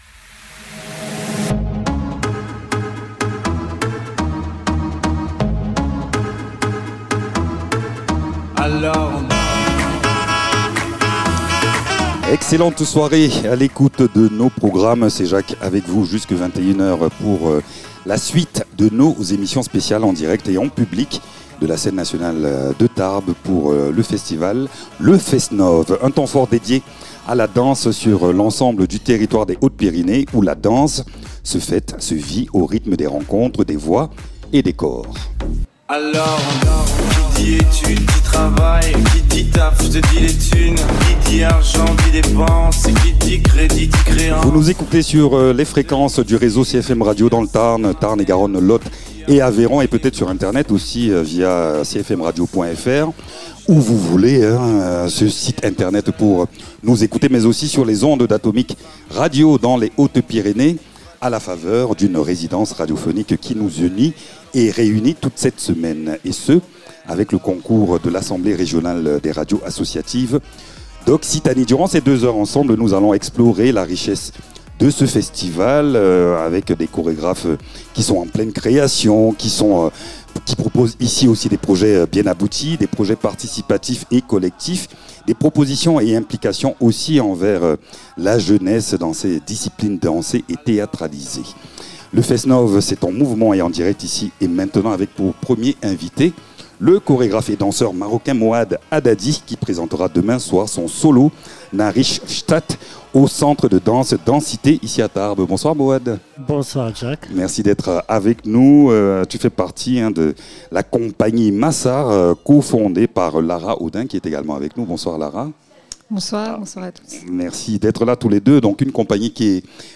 Dans cette interview, nous évoquons la genèse du spectacle, sa pratique et la place de la danse au Maroc. Il est également question de la vision artistique de la compagnie Masse Art, laboratoire de création et d’expérimentation.